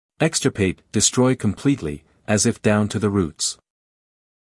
英音/ ˈekstəpeɪt / 美音/ ˈekstərpeɪt /